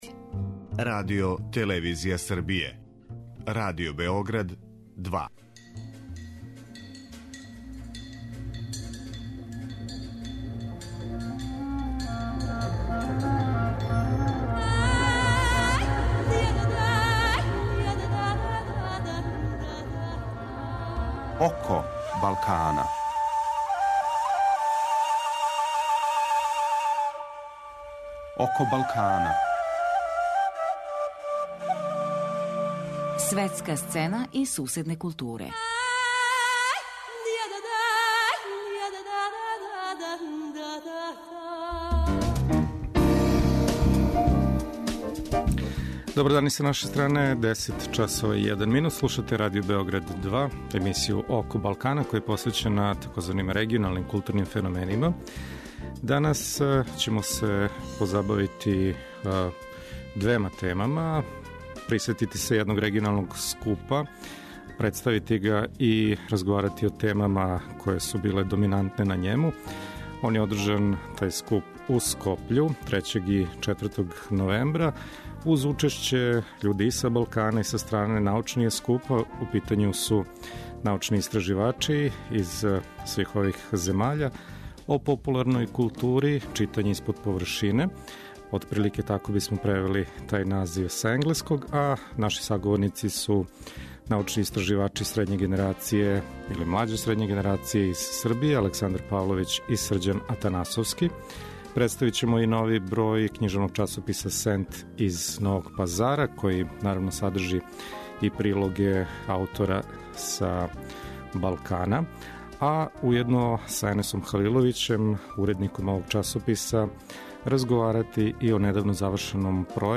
Наши саговорници су двојица научника, учесника овог скупа из Србије